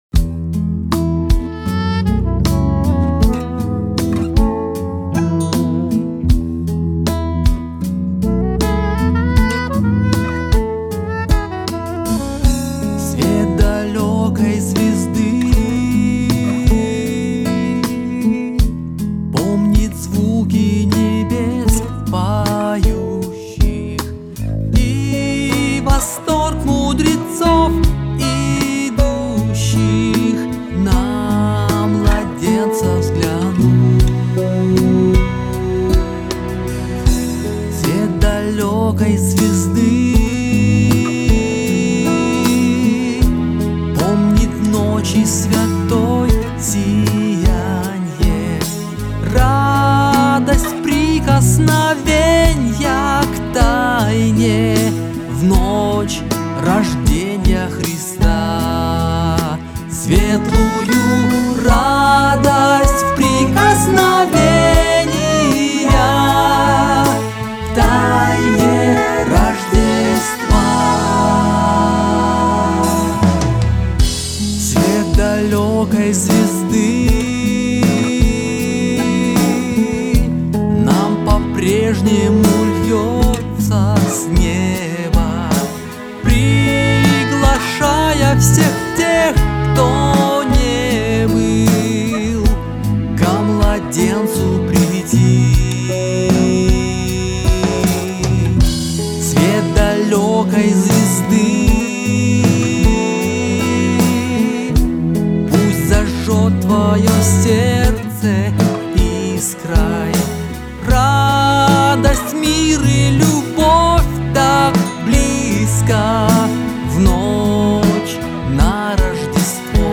562 просмотра 280 прослушиваний 7 скачиваний BPM: 159